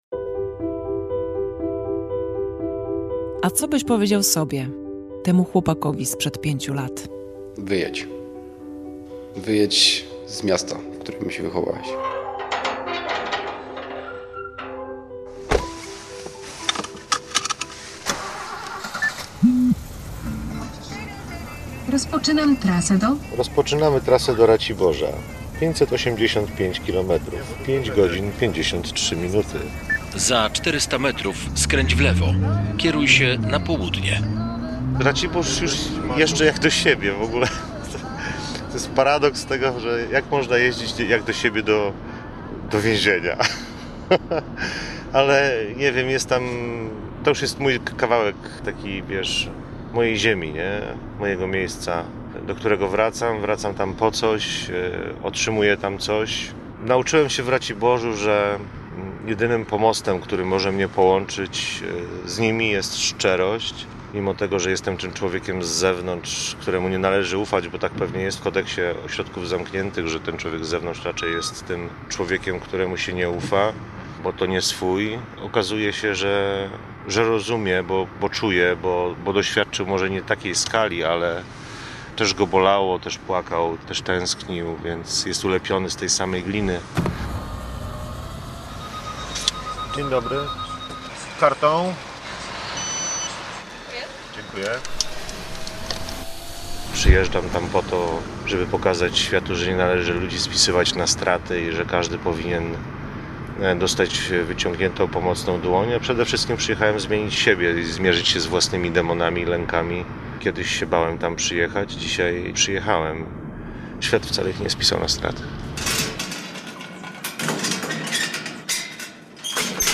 Reportaż „Północ Południe”